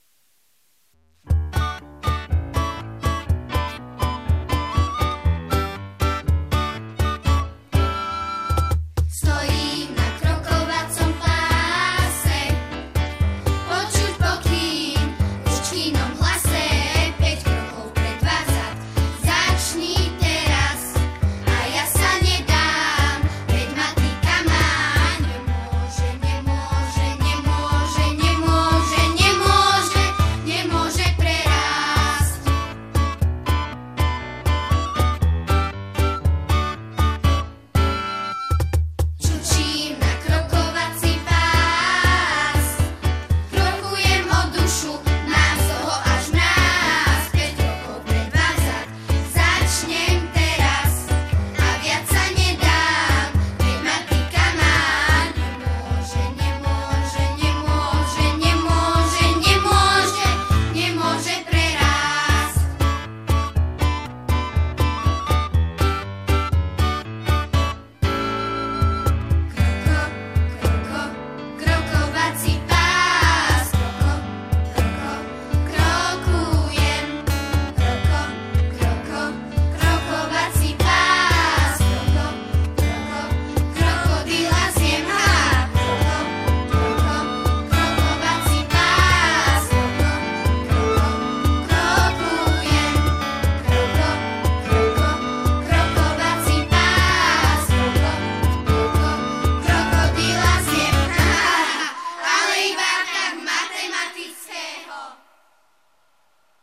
Zároveň si pesničky môžete stiahnuť ako mp3, niektoré aj v dvoch verziách, buď len samostatnú melódiu, alebo aj so spevom.
Krokovací pás (Text, hudba: Bibiana Kľačková, Spev: žiaci SZŠ Dotyk Ružomberok)